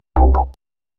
electric-pulse.mp3